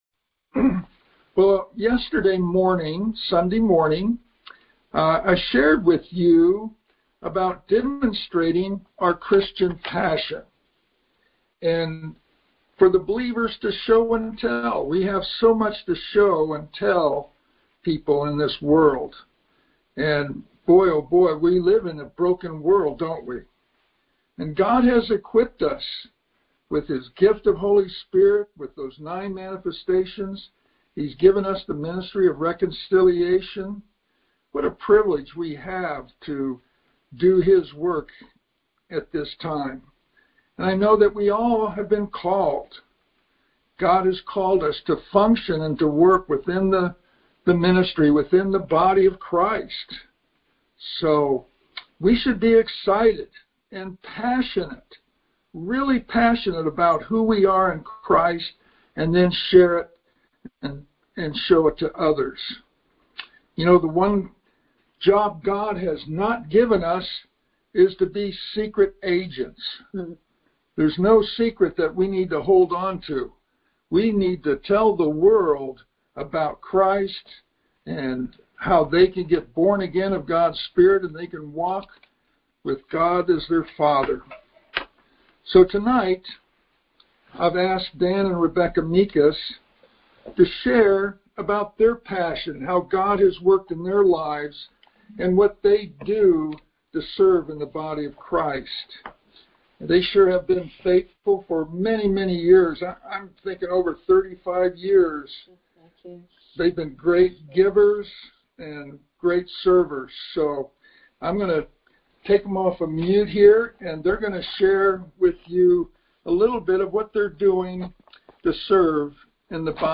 Series: Conference Call Fellowship